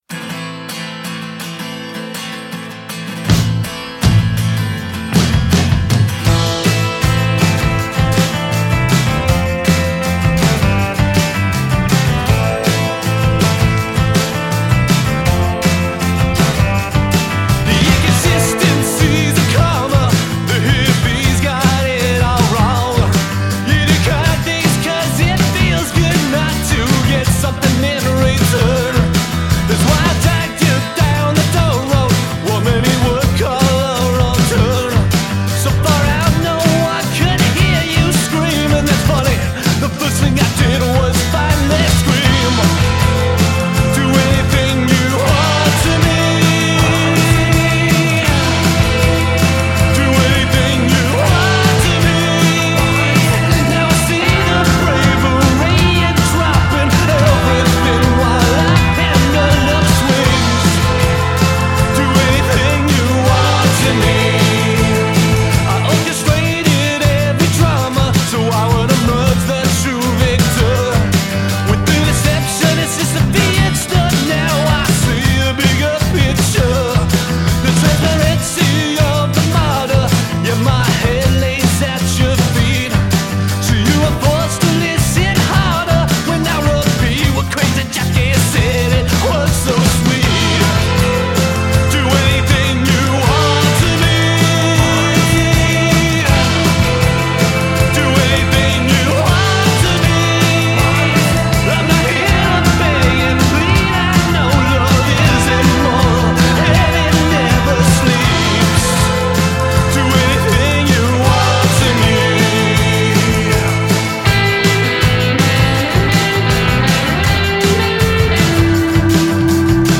A tarnished romantics guide to breathless agitation.
Variously labeled at times Death Doo-Wop
BTW – the production is top-notch and the landscape is lush.